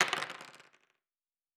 Dice Multiple 6.wav